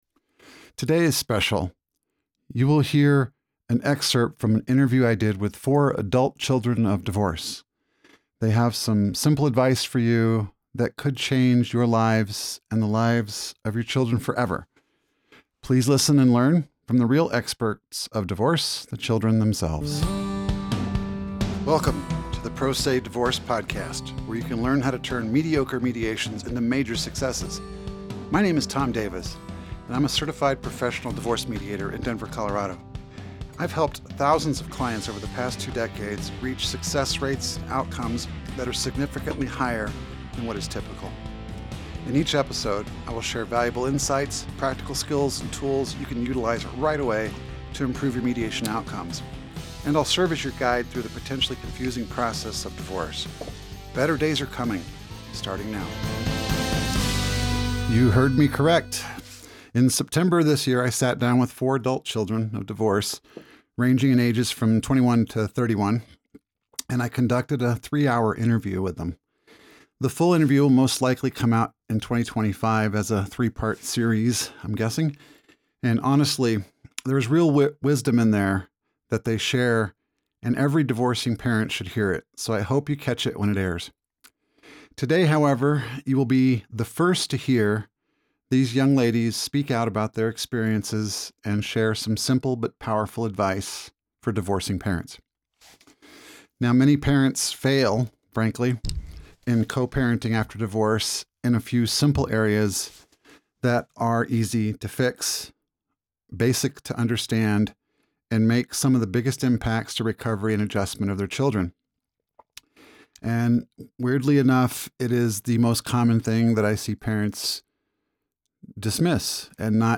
You will here an excerpt from an interview I did with four adult children of divorce. They have some simple advice for you that could change your lives and the lives of your children forever.